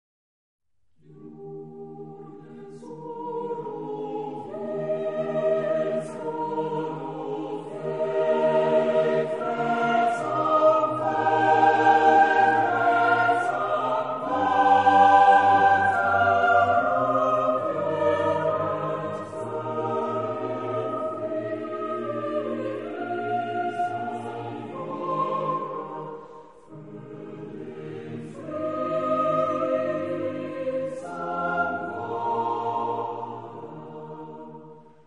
Genre-Style-Forme : Motet ; Sacré
Caractère de la pièce : majestueux ; large
Tonalité : mi bémol majeur